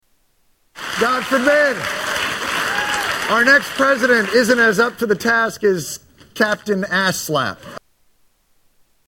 Jon Stewart mocks George W Bush
Category: Comedians   Right: Personal